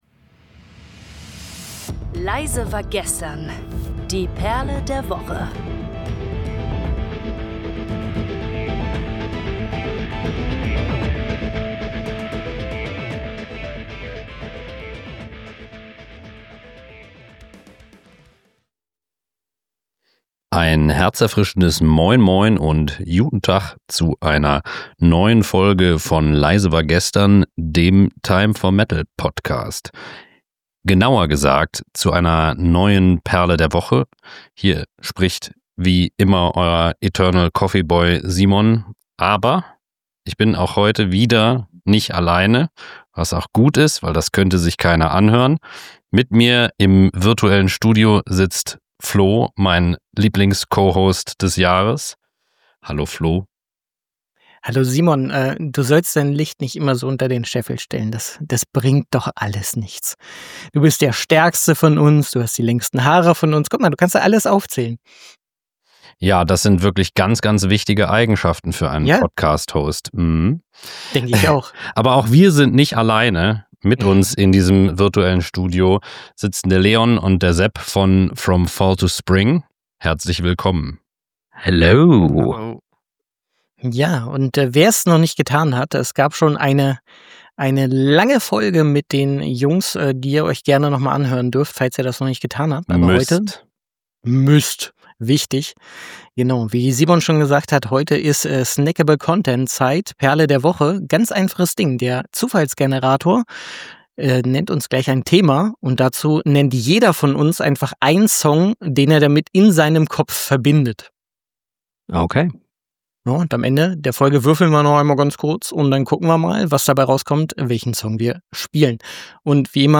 MusikDiskussion